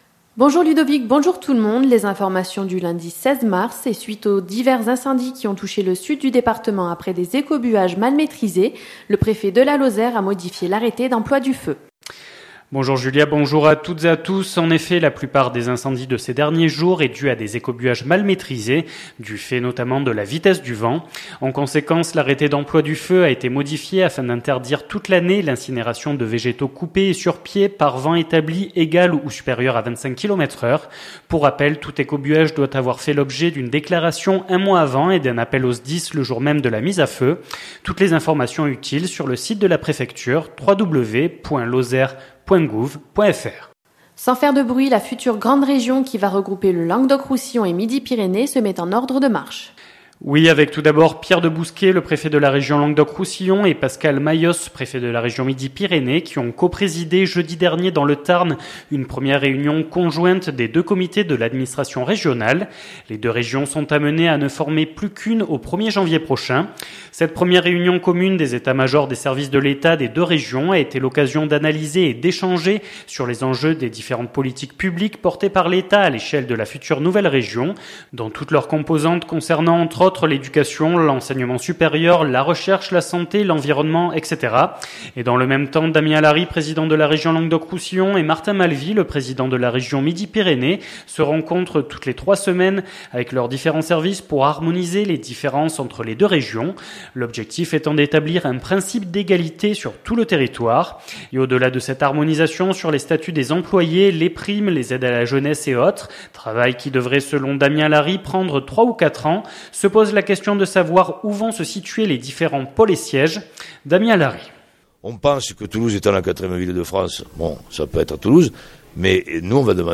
Les informations locales
• La future grande région se met en ordre de marche, interview de Damien Alary, président de la région Languedoc Roussillon